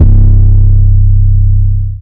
808 6 {C} [ luger ].wav